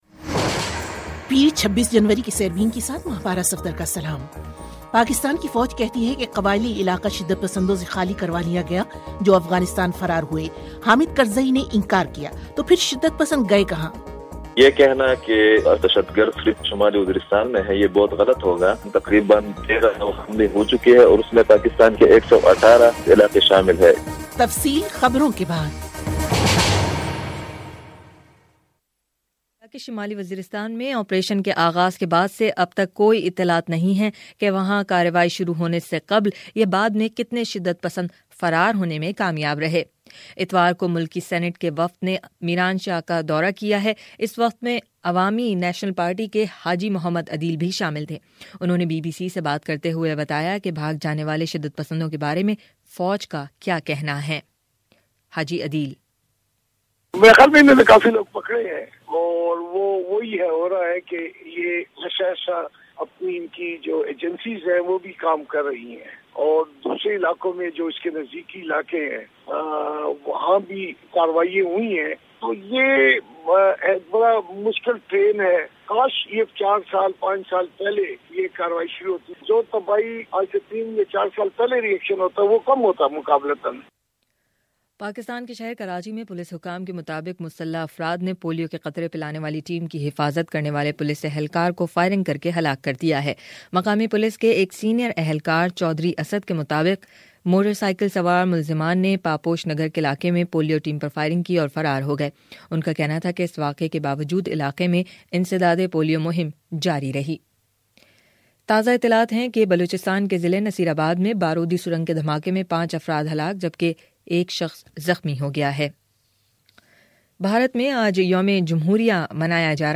بی بی سی اردو کا فلیگ شپ ریڈیو پروگرام روزانہ پاکستانی وقت کے مطابق رات آٹھ بجے پیش کیا جاتا ہے جسے آپ ہماری ویب سائٹ، اپنے موبائل فون، ڈیسک ٹاپ، ٹیبلٹ، لیپ ٹاپ اور اب فیس بُک پر سن سکتے ہیں۔